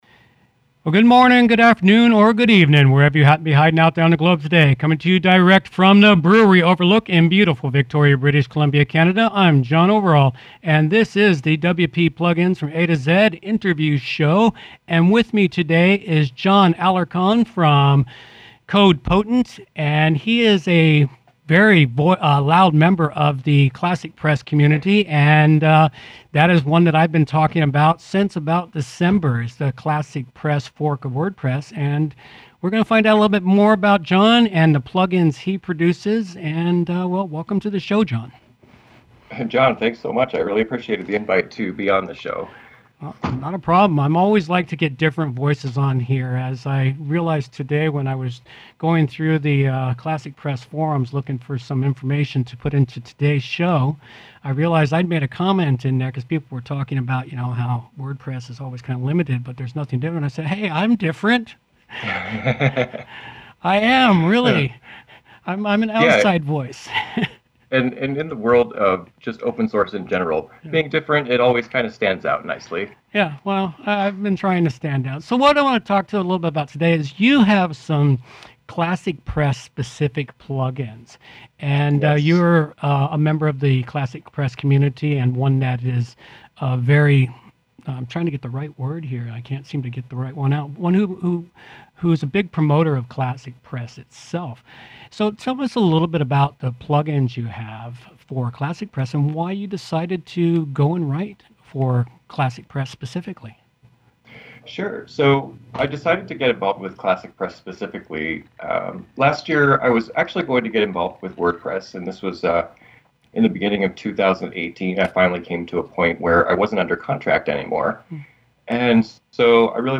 Interview 36